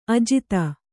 ♪ ajita